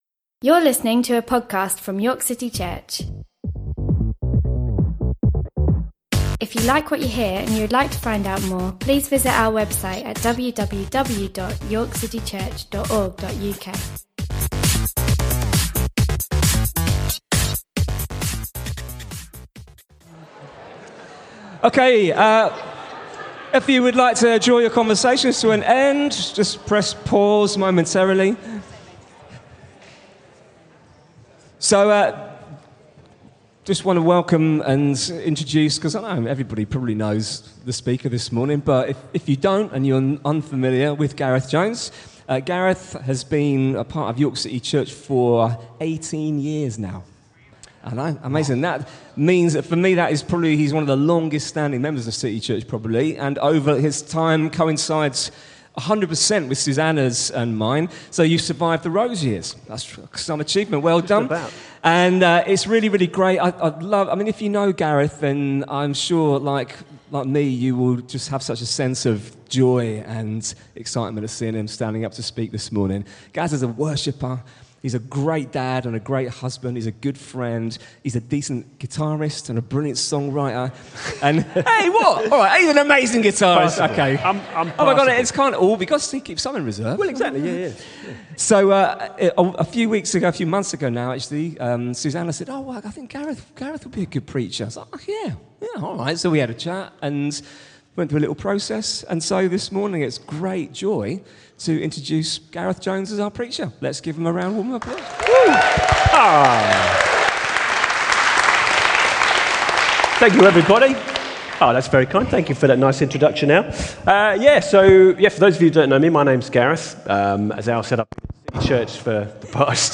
York City Church is a church meeting in the historic city of York. This podcast is primarily a selection of our weekly sermon.